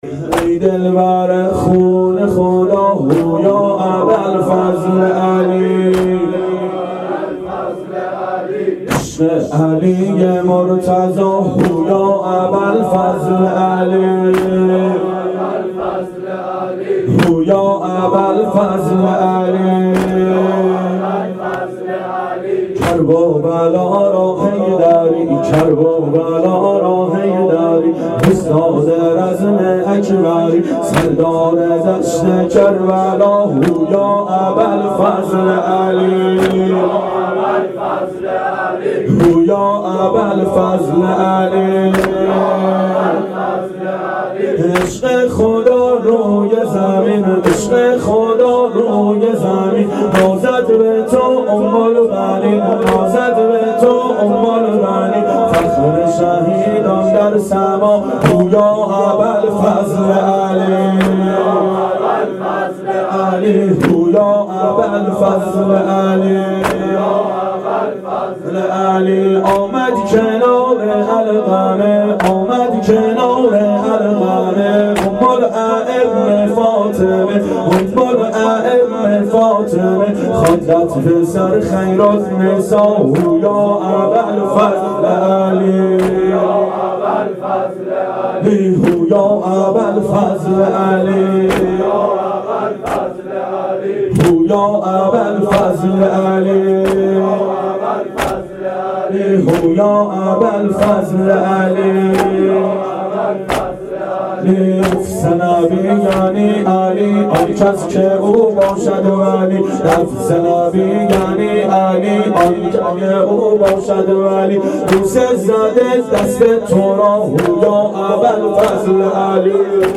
شب عاشورا 92 هیأت عاشقان اباالفضل علیه السلام منارجنبان